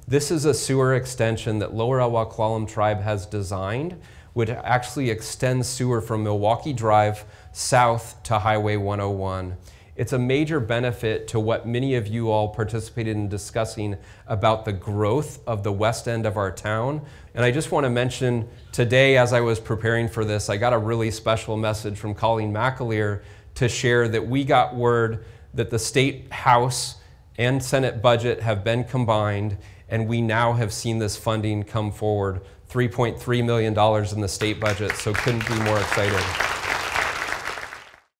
West delivers State of the City address